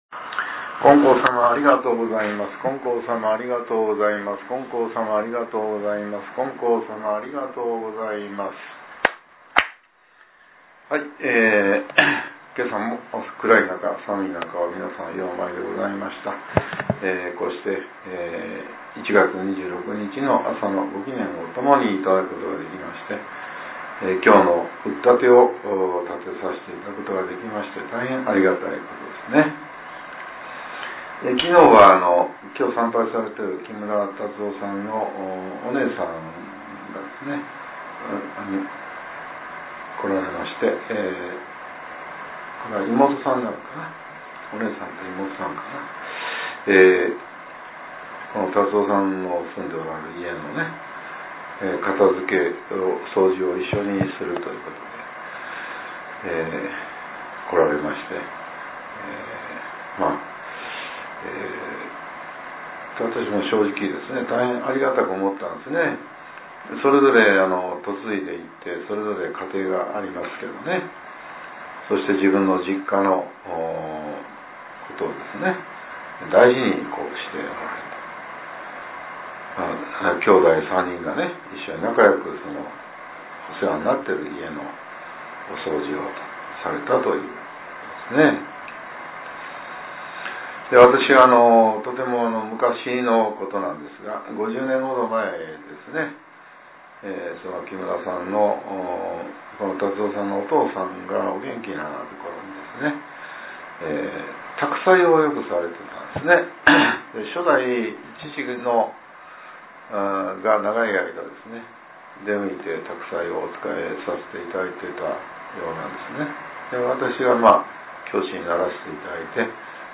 令和７年１月２６日（朝）のお話が、音声ブログとして更新されています。